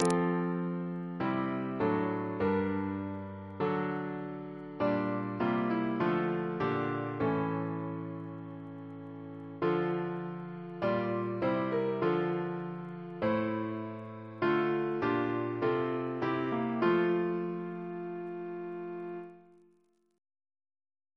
Double chant in F Composer: Samuel S. Wesley (1810-1876), Organist of Hereford and Exeter Cathedrals, Leeds Parish Church, and Winchester and Gloucestor Cathedrals Reference psalters: ACB: 75